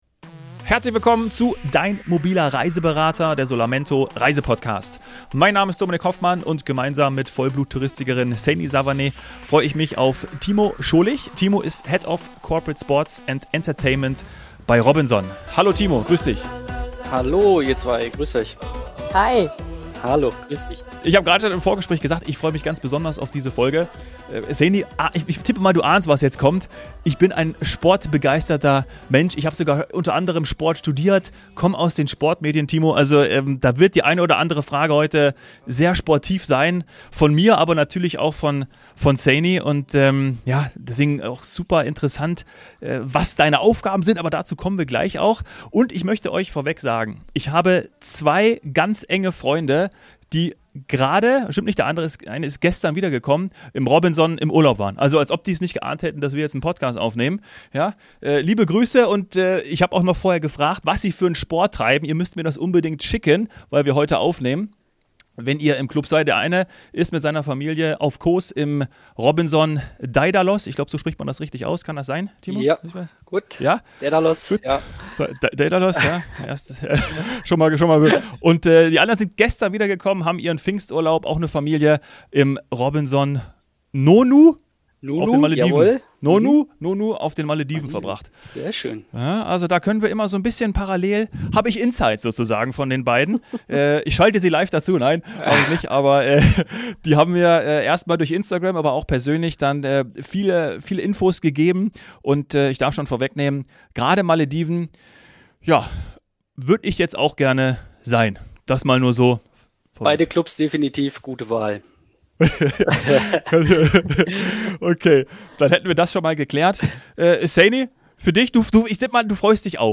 im freudigen Gespräch